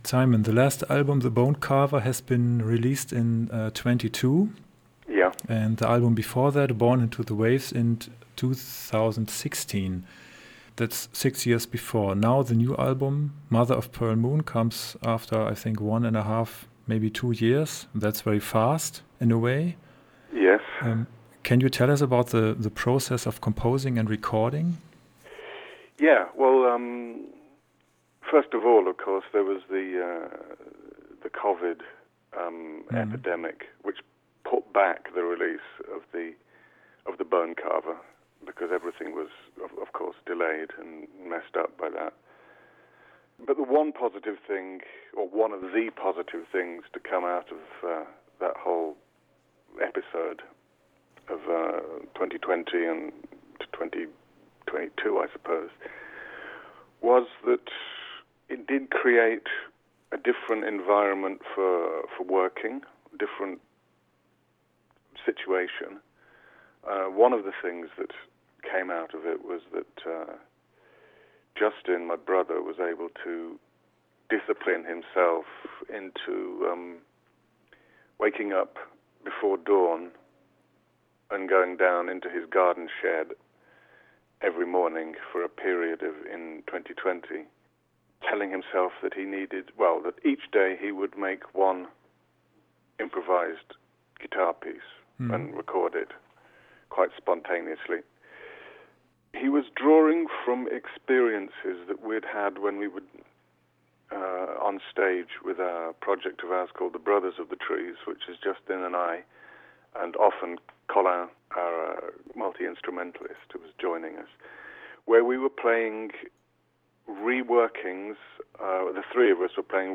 music, art and the dark undercurrent: Interview